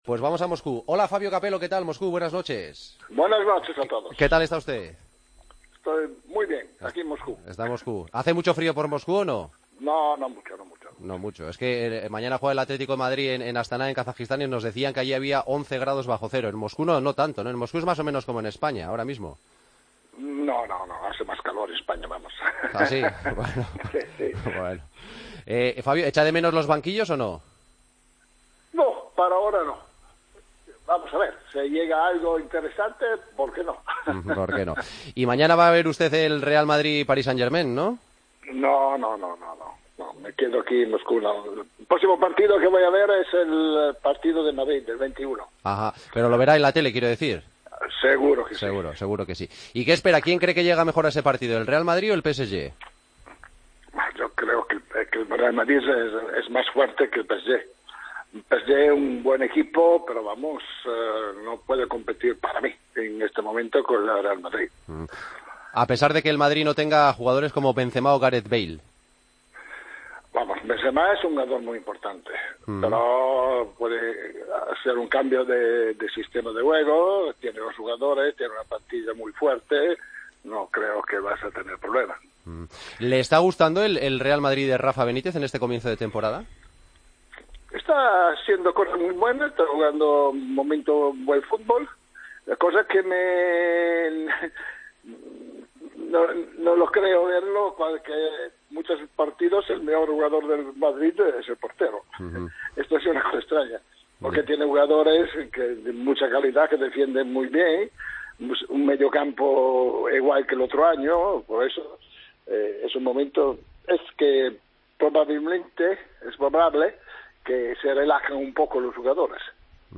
Entrevista a Fabio Capello, en El Partido de las 12